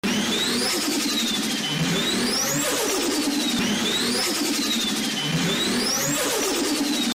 Звуки турбины двигателя
• Качество: высокое
На этой странице вы можете слушать и скачивать онлайн коллекцию реалистичных аудиозаписей: от свиста турбонаддува спортивного автомобиля до оглушительного рева реактивного двигателя.